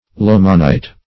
Lomonite \Lom"o*nite\, n.